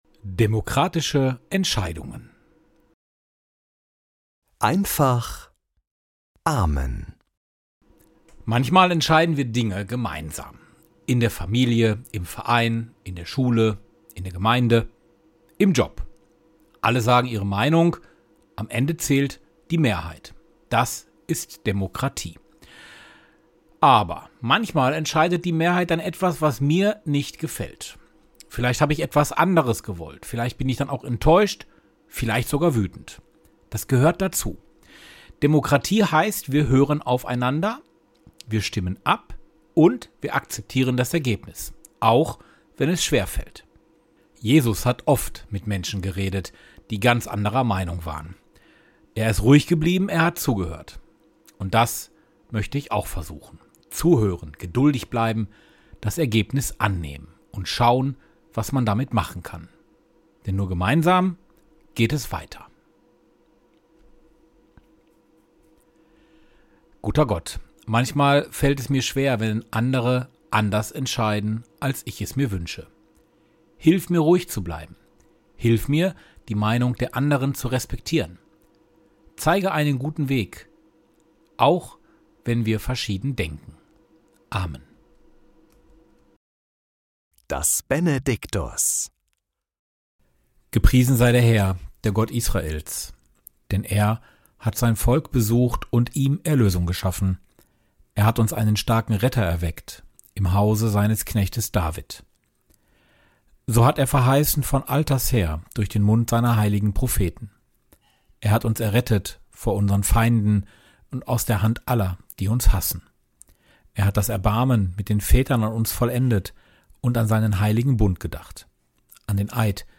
Morgenimpuls in Einfacher Sprache